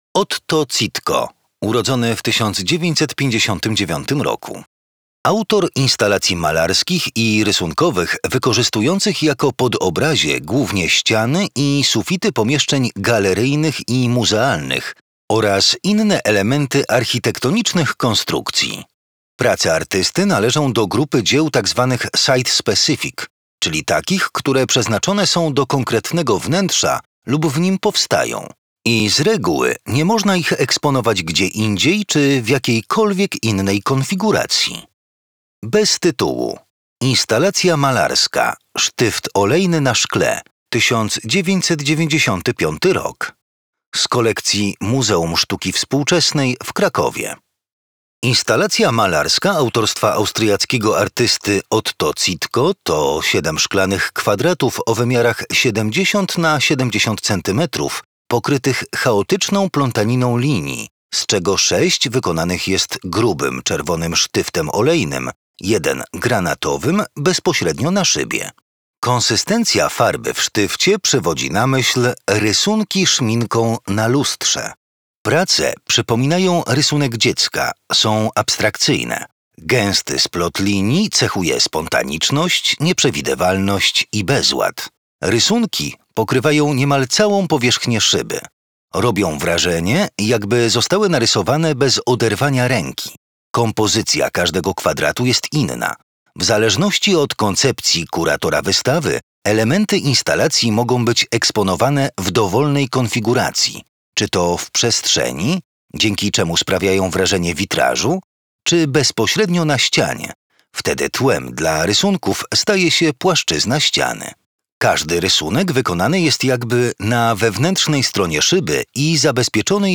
Posłuchaj audiodeksrypcji